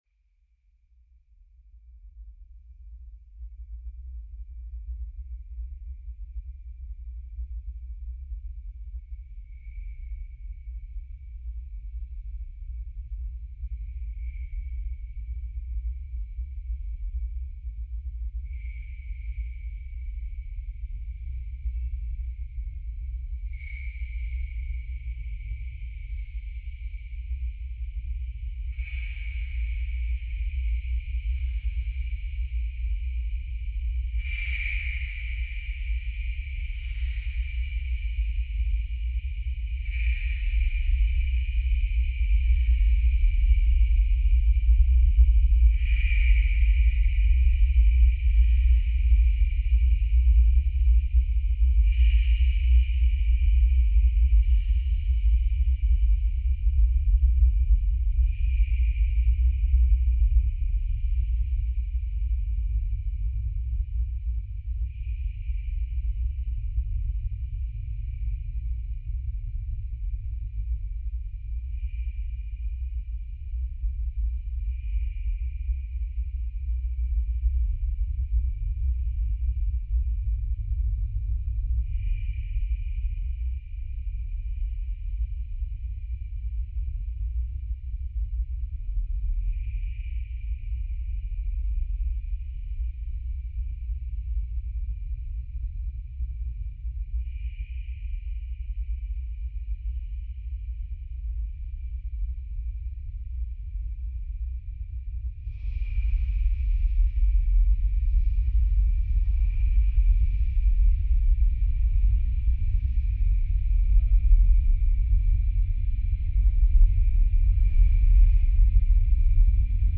sound sculpture ( remixed sound from 2008